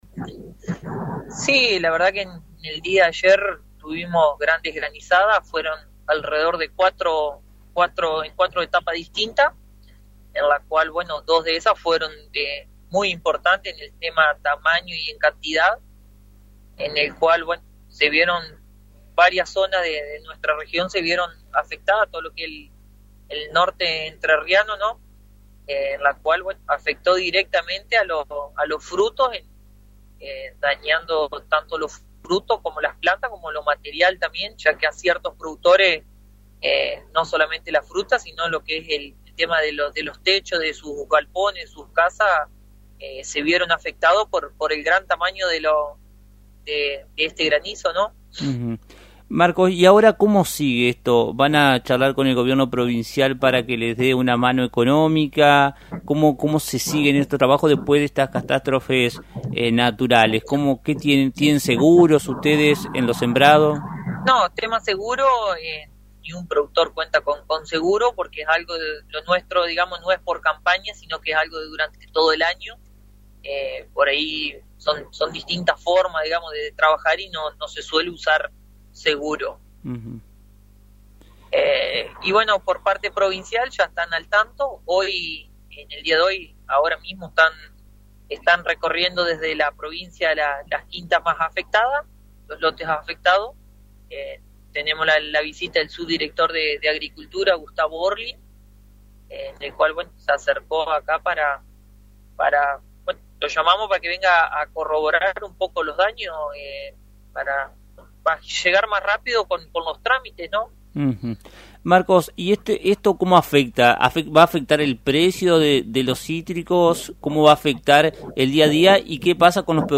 detalló en AM 980 las graves consecuencias que dejó la reciente granizada en el norte entrerriano, afectando tanto la producción como los materiales de los productores.